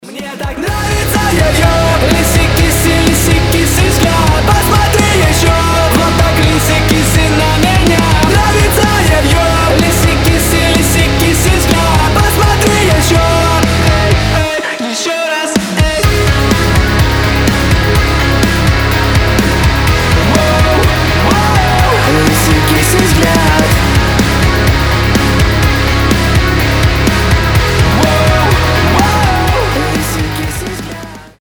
• Качество: 320, Stereo
громкие
Драйвовые
Alternative Metal
Alternative Rock
emo rock